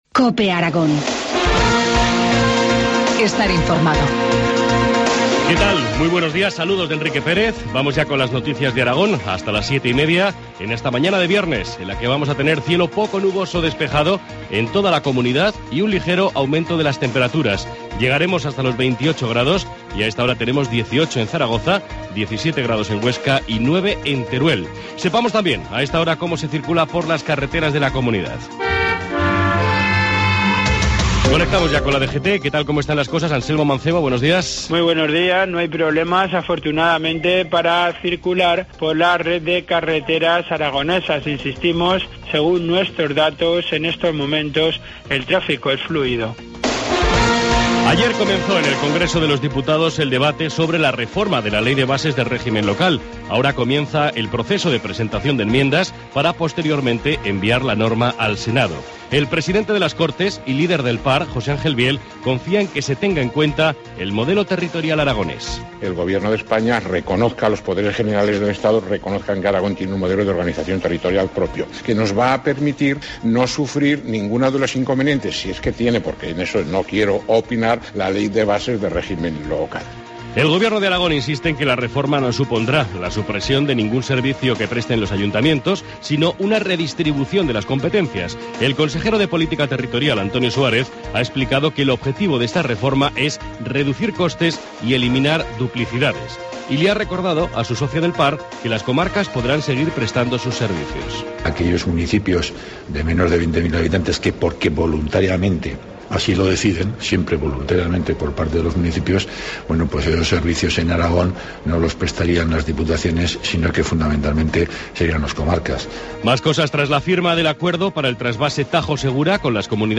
Informativo matinal, viernes 18 de octubre, 7.25 horas